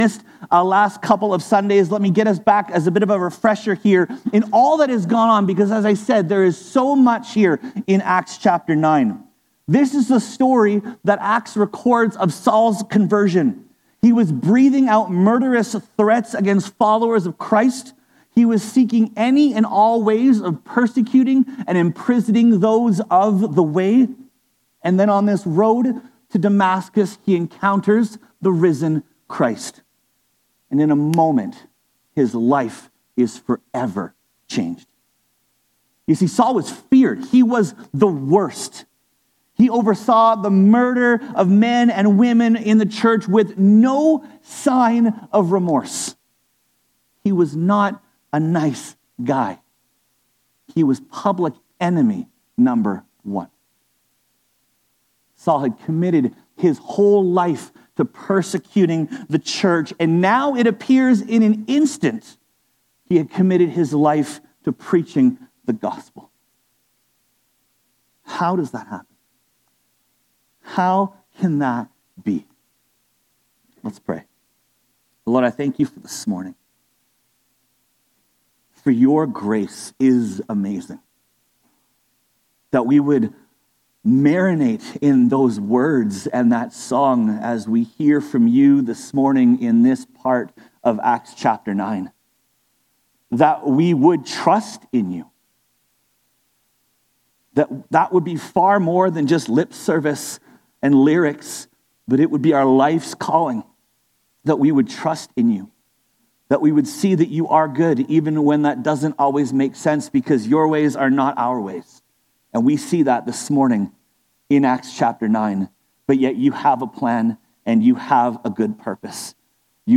Sermons | Westview Baptist Church